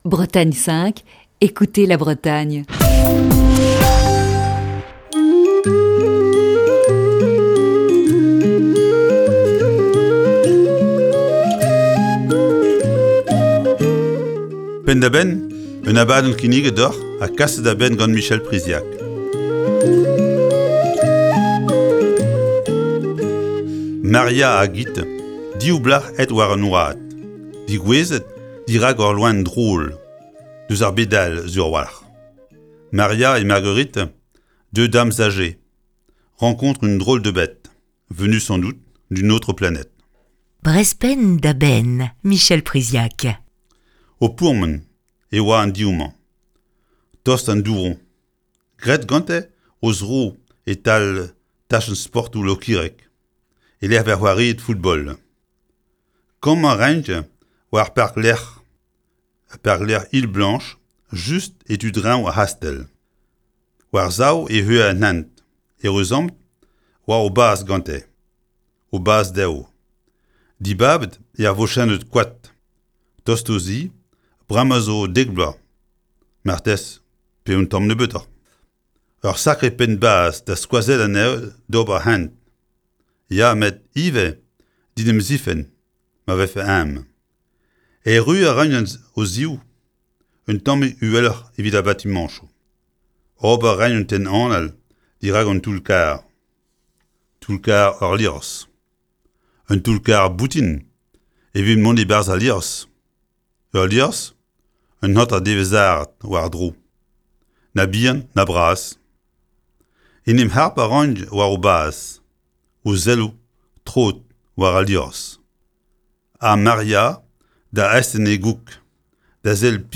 Chronique du 10 août 2020.
(Chronique diffusée le 7 octobre 2019).